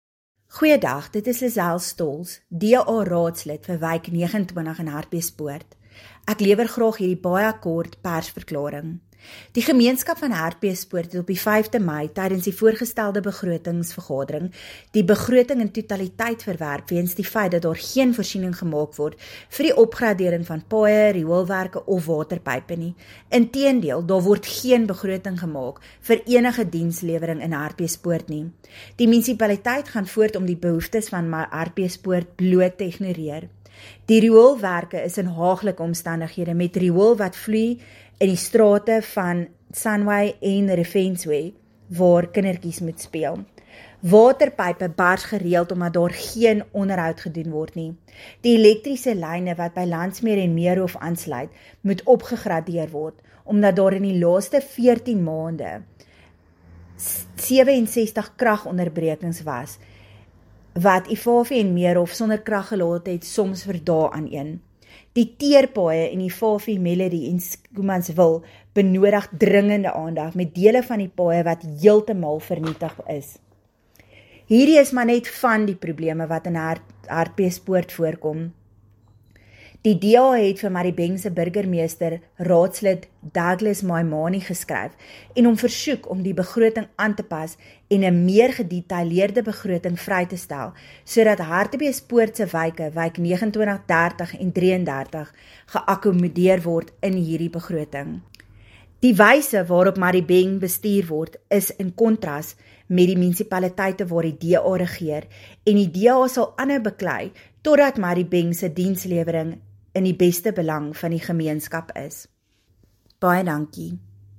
Afrikaans by Cllr Lizelle Stoltz.
Cllr-Lizelle-Stoltz-Madibeng-Budget-Afr.mp3